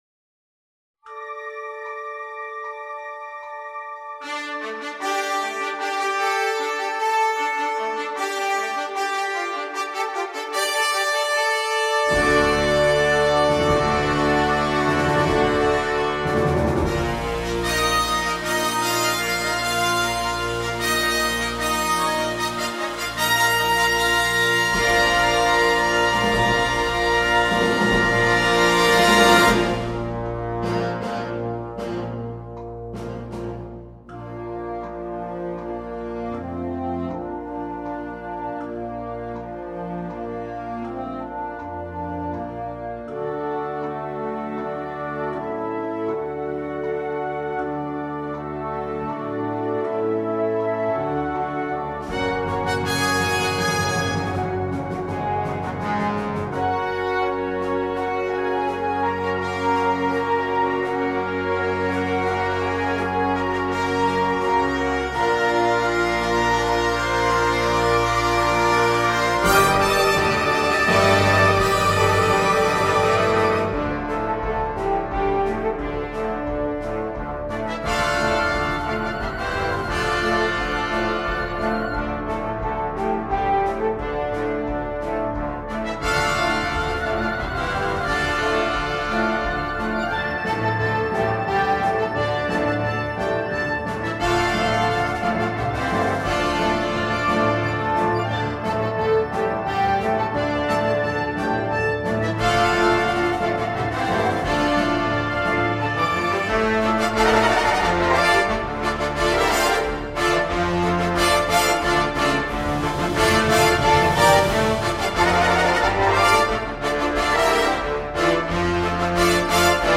Banda completa
Obras originales, música de apertura